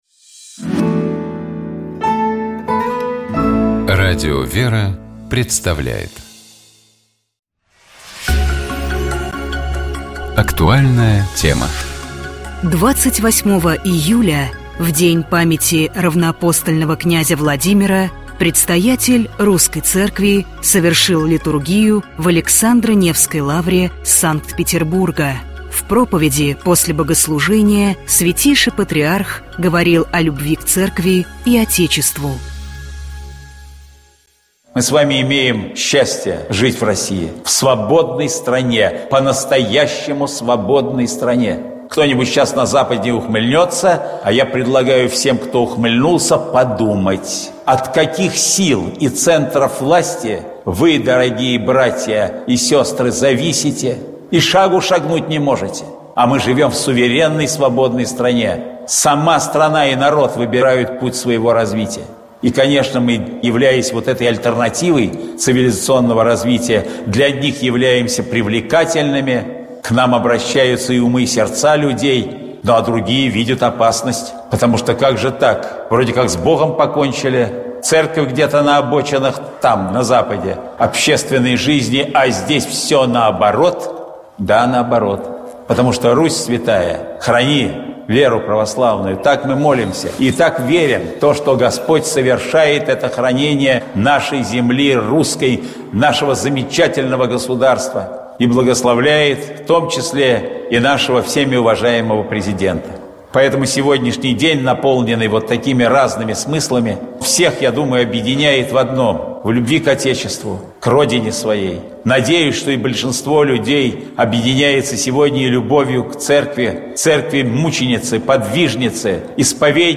Проповедь Святейшего патриарха Московского и всея Руси Кирилла в День Крещения Руси
28 июля в день памяти равноапостольного князя Владимира Предстоятель Русской Церкви совершил Литургию в Александро-Невской лавре Санкт-Петербурга.